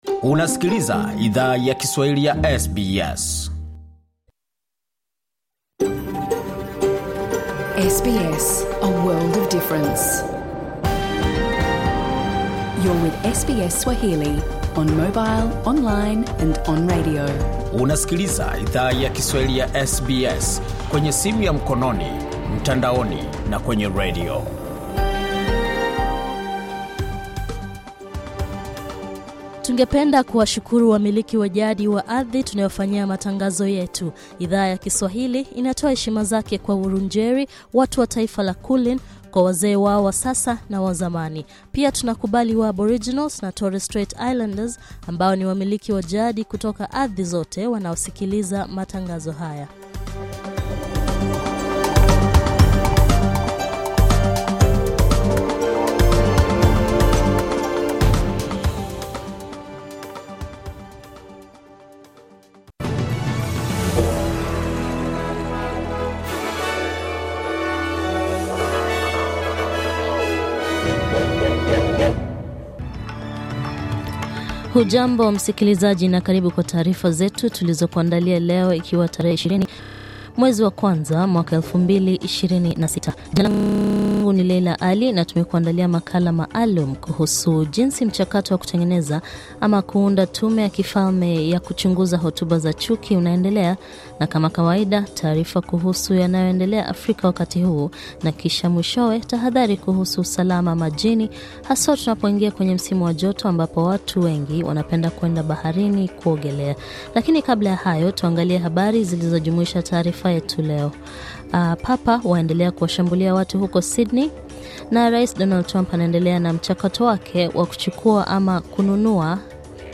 Taarifa ya habari:Nchi za Ulaya zajiandaa kutetea eneo la Denmark